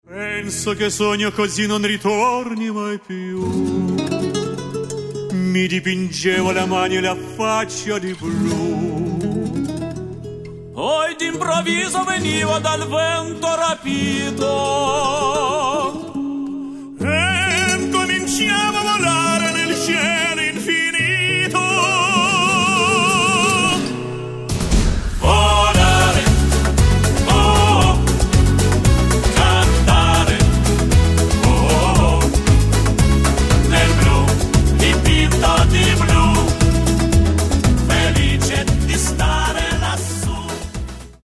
Каталог -> Эстрада -> Группы